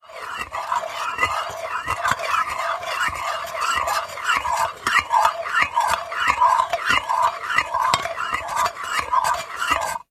Звук кручения ложкой в бидоне